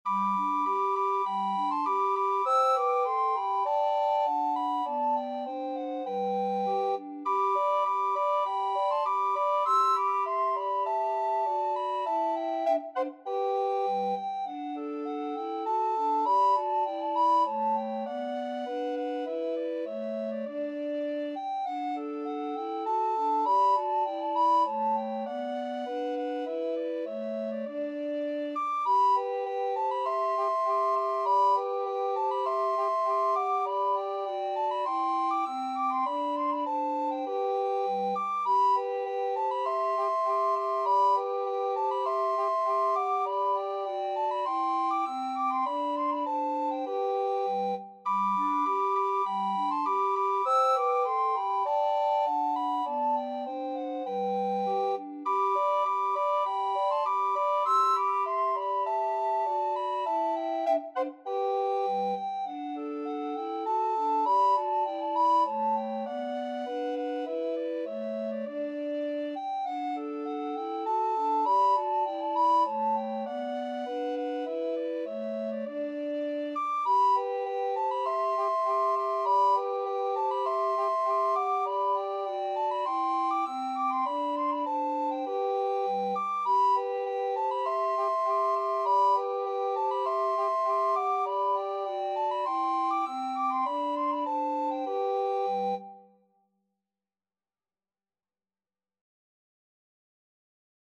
Free Sheet music for Recorder Trio
Soprano RecorderAlto RecorderBass Recorder
G major (Sounding Pitch) (View more G major Music for Recorder Trio )
~ = 200 A1
2/2 (View more 2/2 Music)
Traditional (View more Traditional Recorder Trio Music)